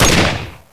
fire-01.ogg